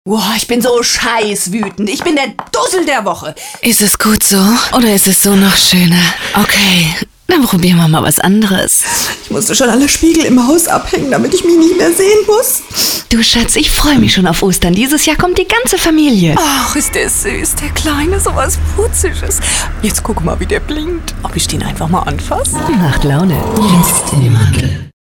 Kein Dialekt
Sprechprobe: Industrie (Muttersprache):
female german voice over talent